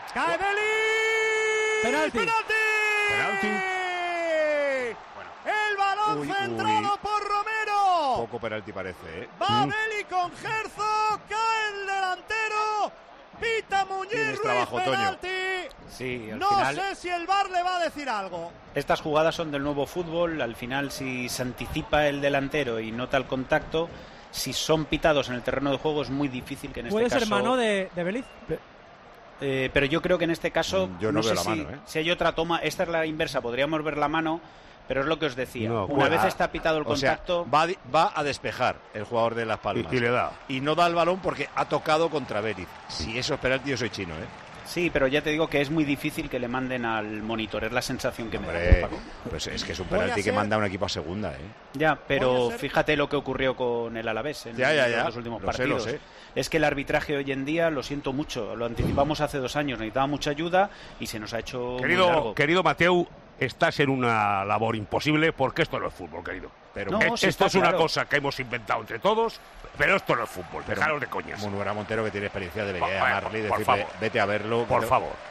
Ahora añadimos la opinión del excolegiado Mateu Lahoz, quien valoró en Tiempo de Juego de Cope esta acción polémica a favor del Espanyol: "Estas jugadas son del nuevo fútbol. Si el delantero se anticipa y nota el contacto, si son pitados en el terreno de juego...".